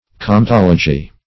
Search Result for " cometology" : The Collaborative International Dictionary of English v.0.48: Cometology \Com`et*ol"o*gy\, n. [Comet + -logy.] The branch of astronomy relating to comets.
cometology.mp3